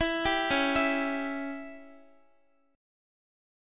Dbdim chord